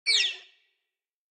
File:Sfx creature seamonkeybaby hold 03.ogg - Subnautica Wiki
Sfx_creature_seamonkeybaby_hold_03.ogg